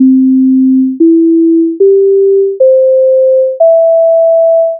reminder_melody1
reminder_melody1.wav